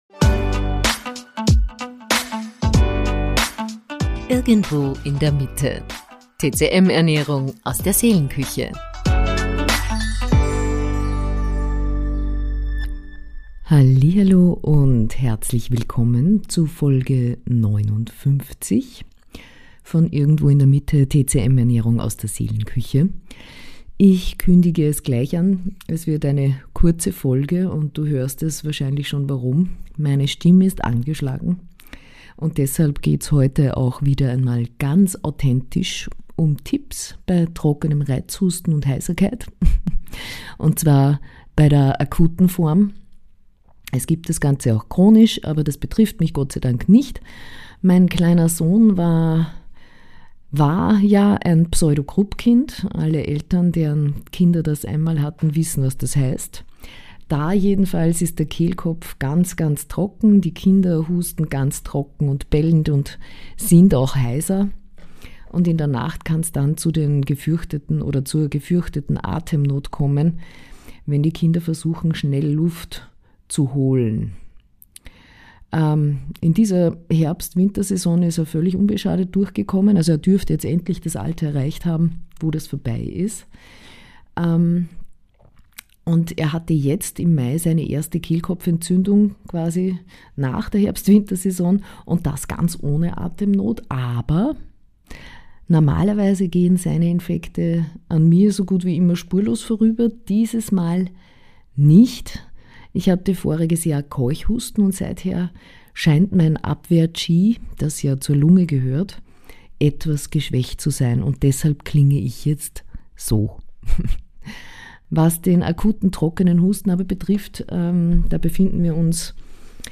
In dieser kurzen, etwas heiseren Folge teile ich mit Dir, was bei